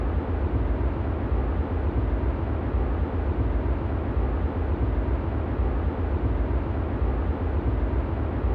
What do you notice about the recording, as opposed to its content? Test cases C: xR = 500 m, medium turbulence and grass ground in summer.